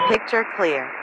Radio-awacsPictureClear1.ogg